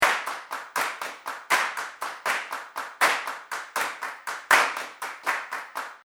6/8 time clap
You may notice that your clapping or counting pattern follows a pattern of one-two-three-four-five-six, with beat 1 being the strongest, and beat 4 also getting some emphasis to signal the beginning of the second half of the bar.
6_8-clap-120-BPM.mp3